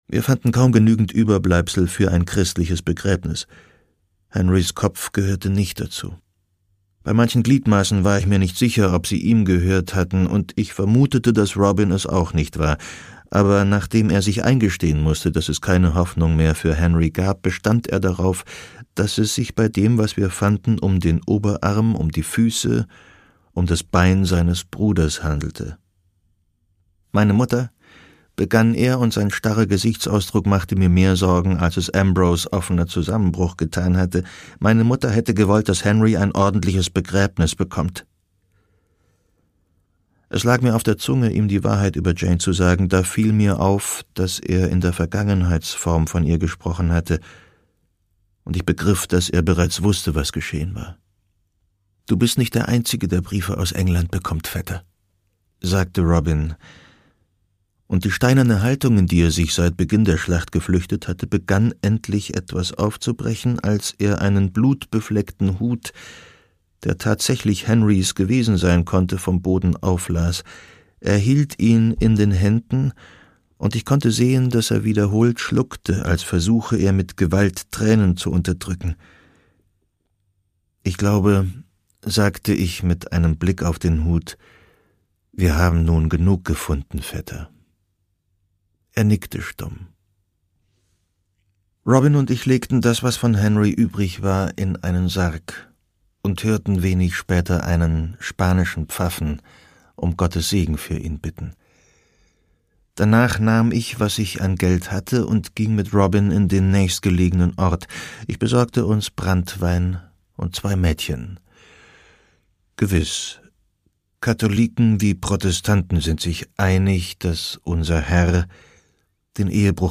Produkttyp: Hörbuch-Download
Fassung: Autorisierte Lesefassung